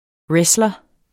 Udtale [ ˈɹεslʌ ]